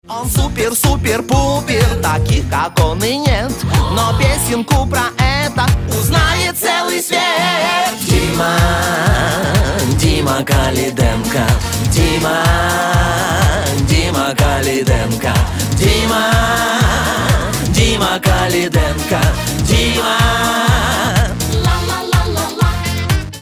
веселые
попса